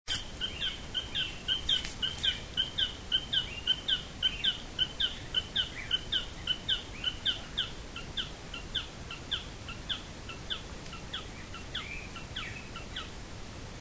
Magellanic Tapaculo (Scytalopus magellanicus)
Life Stage: Adult
Country: Chile
Location or protected area: Valdivia
Condition: Wild
Certainty: Recorded vocal
Scytalopus_magellanicus_valdivia_iphone.mp3